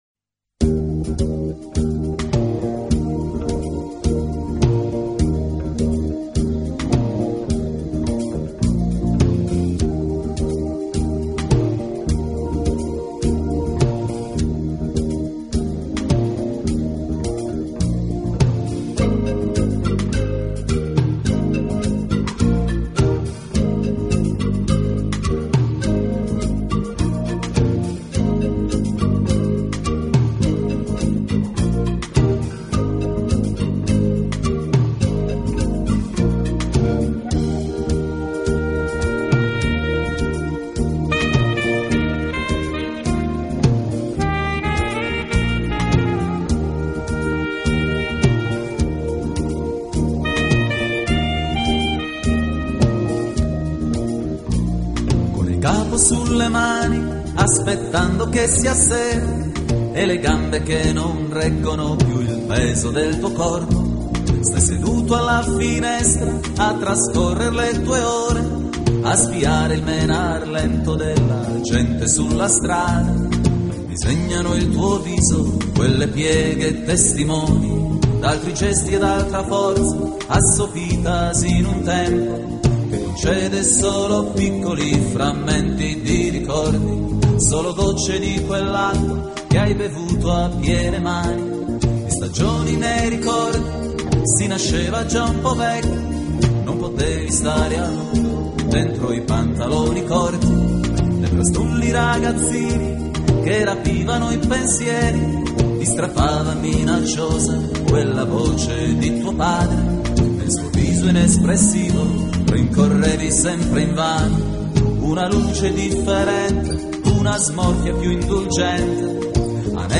【世界音乐】